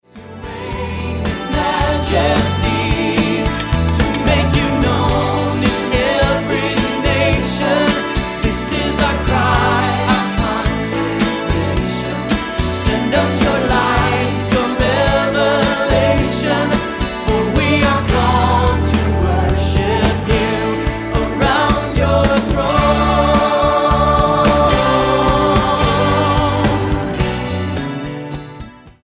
STYLE: MOR / Soft Pop
with enthusiastic praise & worship